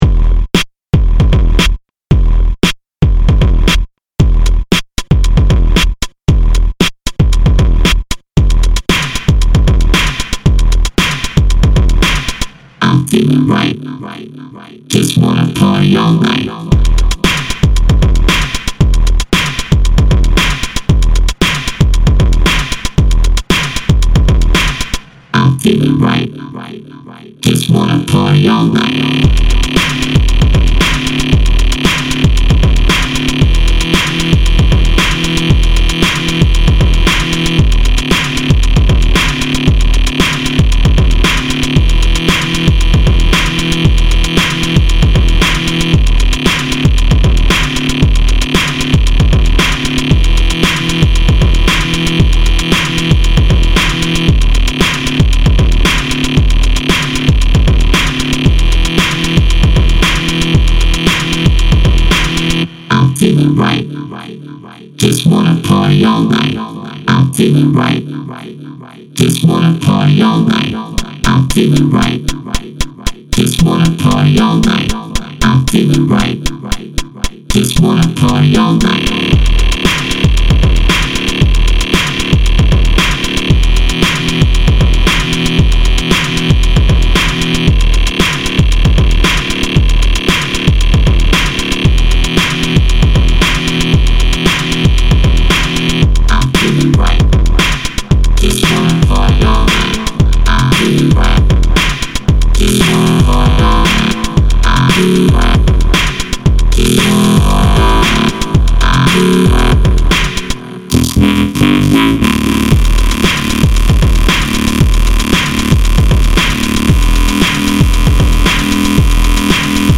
Electro vocoder jam for late nights when you’re all right.